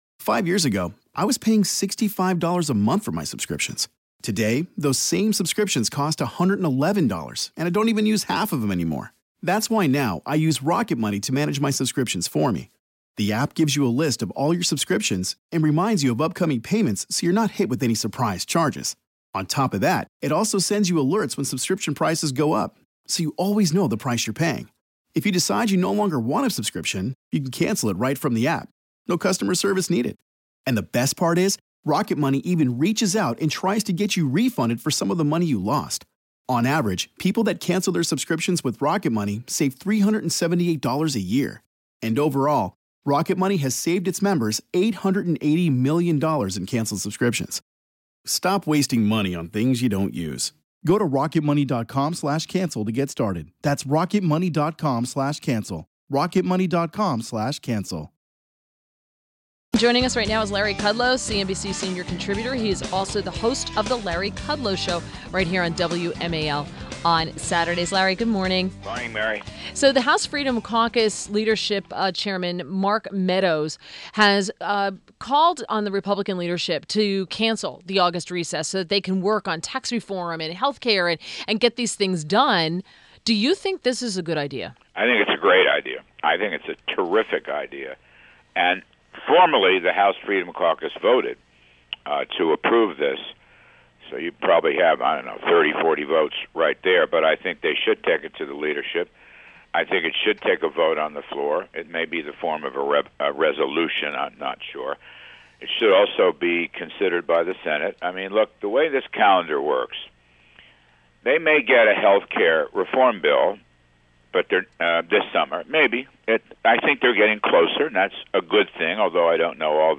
WMAL Interview - LARRY KUDLOW 06.13.17